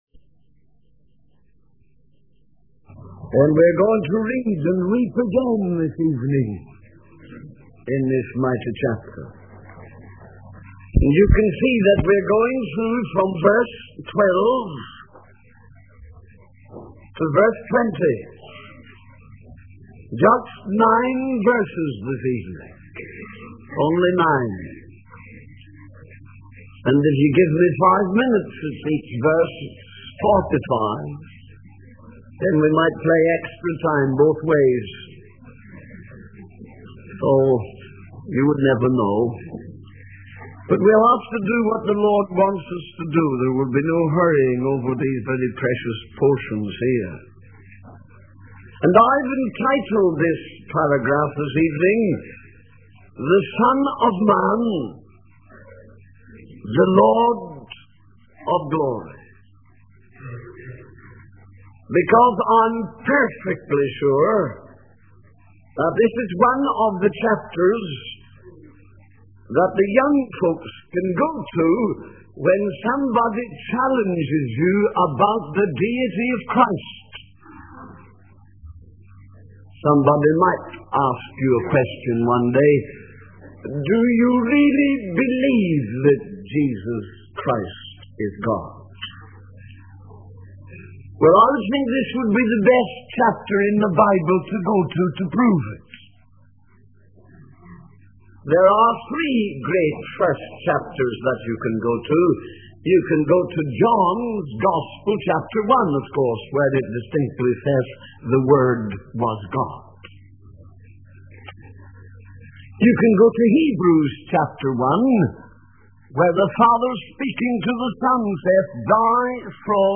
In this sermon, the preacher discusses the power of the Word of God and how Jesus used it effectively. He gives examples from the Bible, such as when Jesus was tempted by the devil in the wilderness and how he responded with the Word of God.